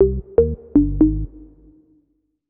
Melodic down.wav